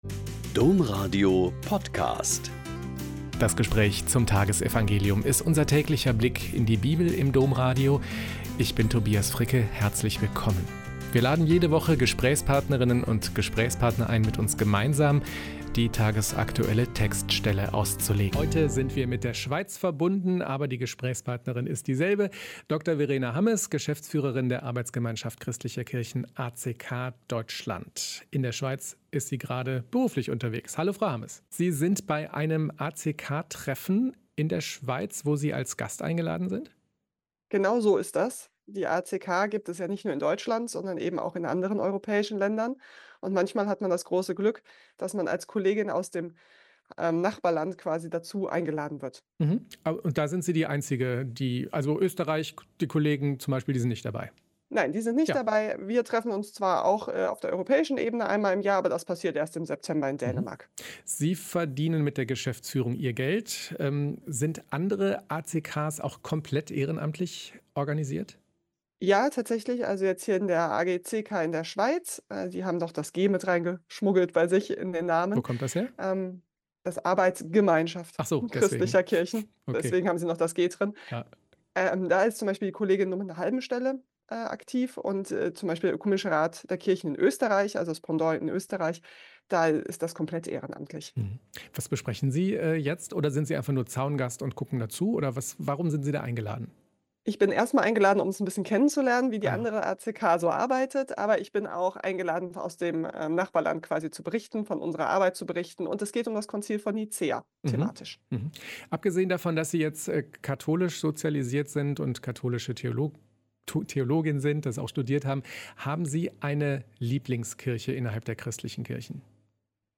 Lk 13,3-7 - Gespräch